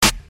Snares
nt - mstk snare 1.wav